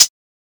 edm-hihat-48.wav